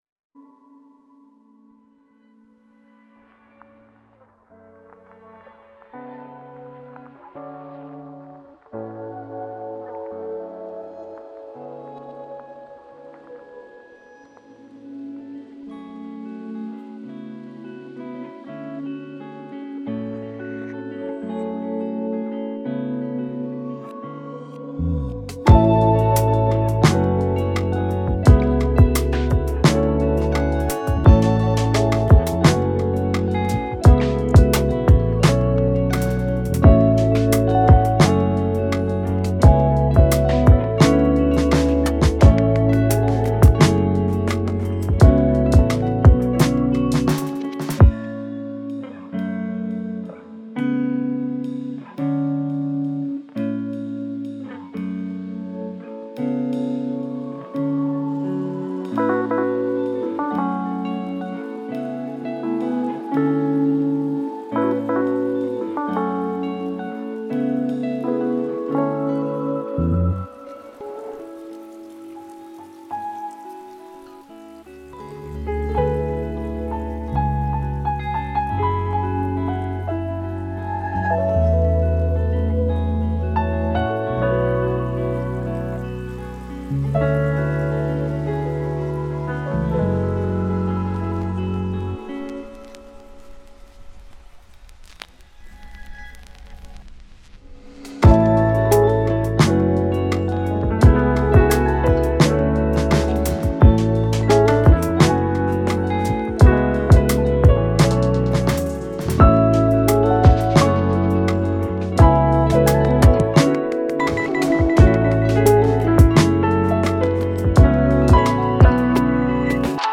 موسیقی بی کلام هیپ هاپ
سبک آرامش بخش , پیانو , موسیقی بی کلام , هیپ‌هاپ